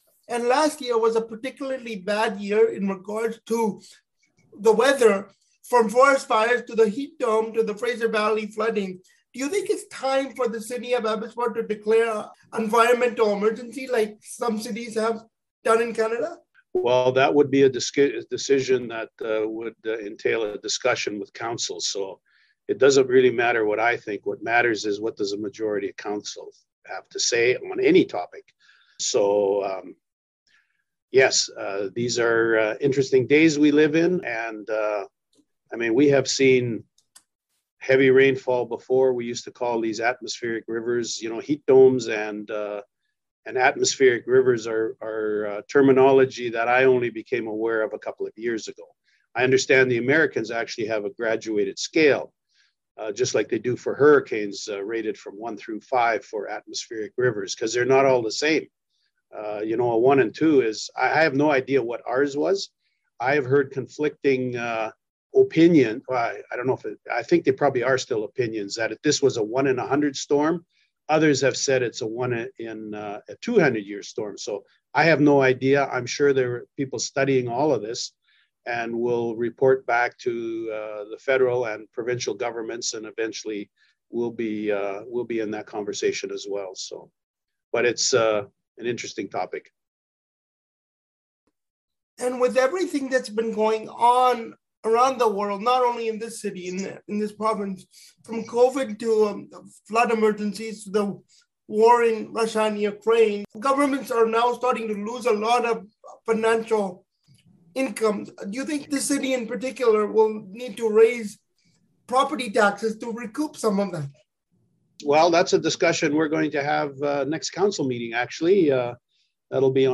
Listen to the CIVL interview with Mayor Braun below:
Abbotsford-mayor-interview-part-2.mp3